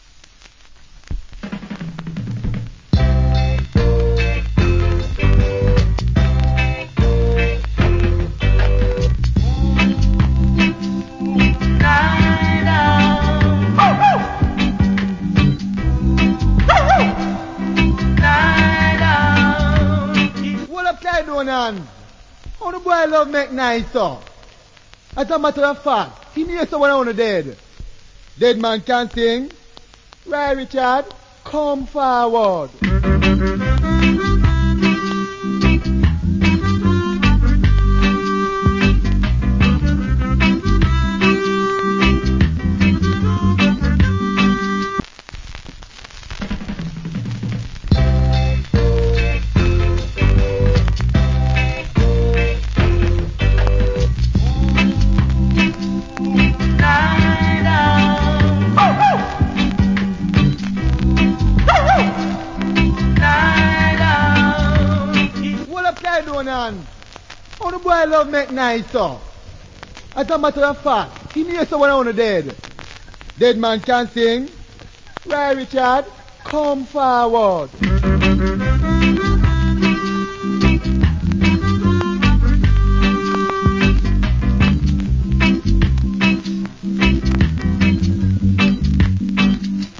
Wicked Harmonica Reggae.